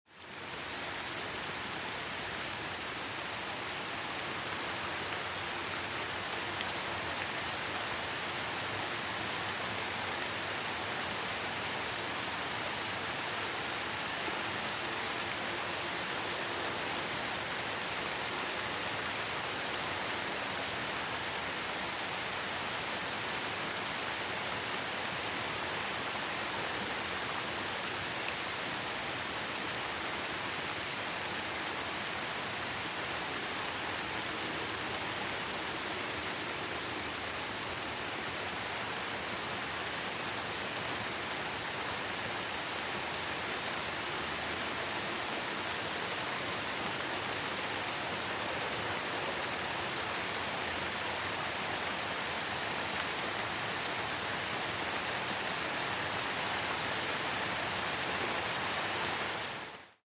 دانلود صدای باران 7 از ساعد نیوز با لینک مستقیم و کیفیت بالا
جلوه های صوتی
برچسب: دانلود آهنگ های افکت صوتی طبیعت و محیط دانلود آلبوم صدای نم نم باران از افکت صوتی طبیعت و محیط